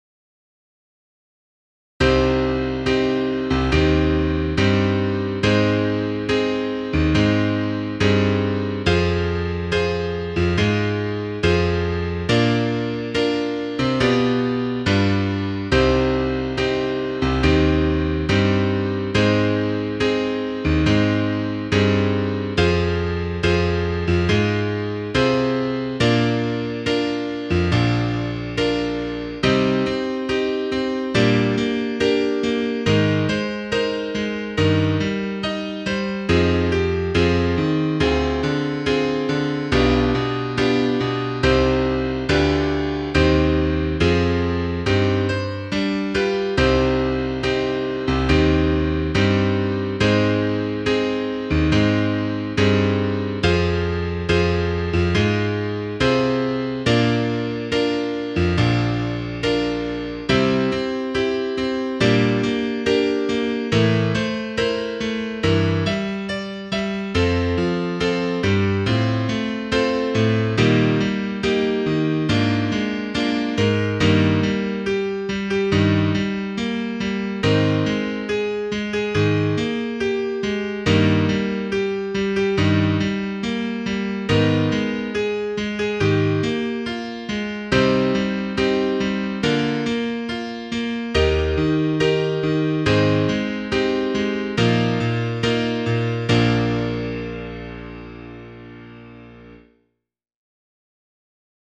Piano
10_Sicut_locutus_est_Piano.mp3